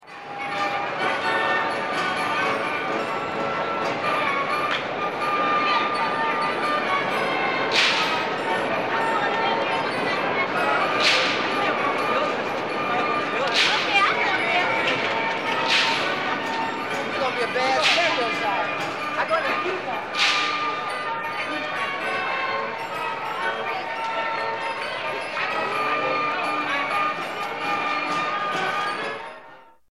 PARQUE ATRACCIONES SONIDOS
Ambient sound effects
Descargar EFECTO DE SONIDO DE AMBIENTE PARQUE ATRACCIONES SONIDOS - Tono móvil
Parque_atracciones_sonidos.mp3